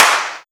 pcp_clap04.wav